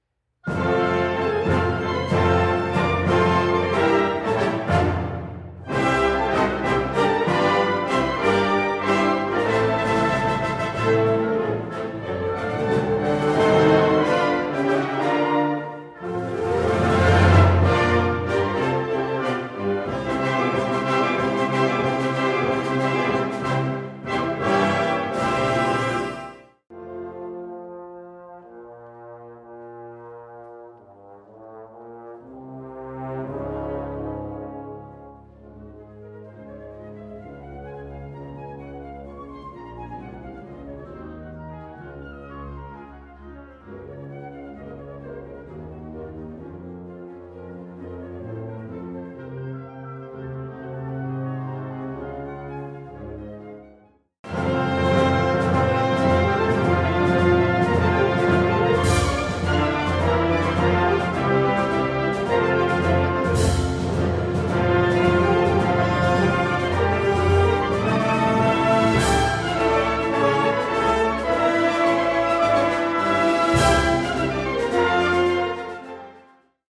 F Major（原調はG Major）
特にエンディングの堂々とした金管のユニゾンが印象的です。